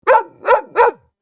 Barking Dog Cute Sound Effect Download: Instant Soundboard Button
Dog Barking Sound318 views